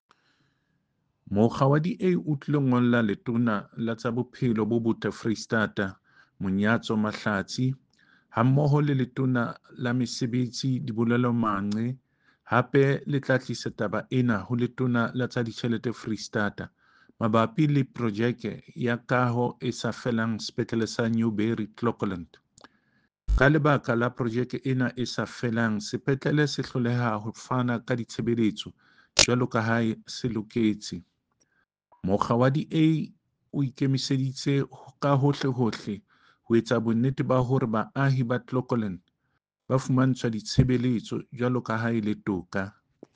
Sesotho soundbites by David Masoeu MPL and